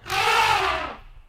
wildlife_elephant.ogg